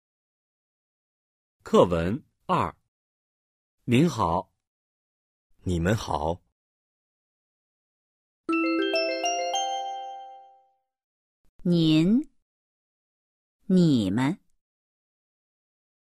#2. Hội thoại 2